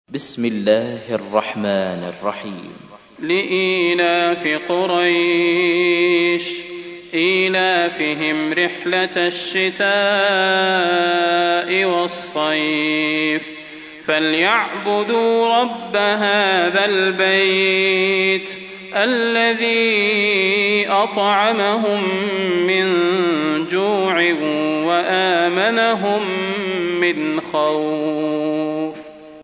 تحميل : 106. سورة قريش / القارئ صلاح البدير / القرآن الكريم / موقع يا حسين